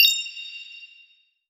Airy Bell Notification.wav